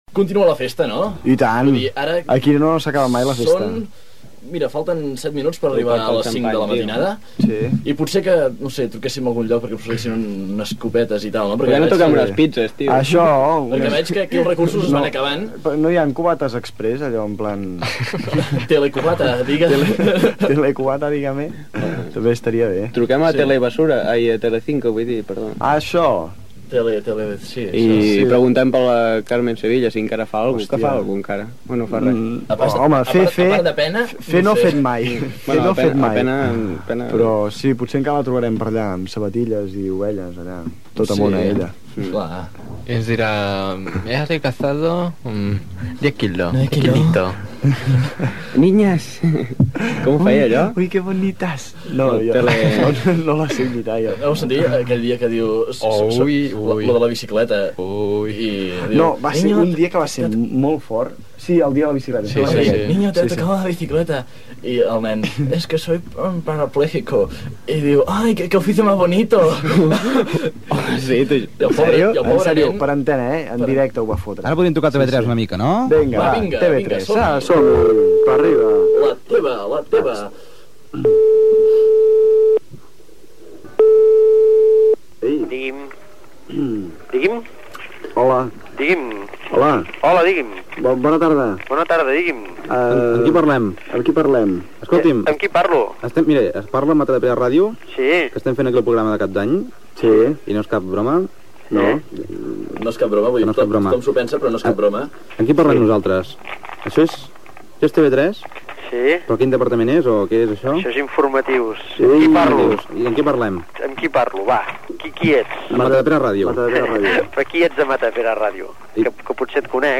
Conversa telefònica entre els col·laboradors del programa i professionals dels mitjans de comunicació.
Entreteniment